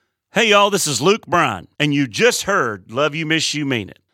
LINER Luke Bryan (LYMYMI) 5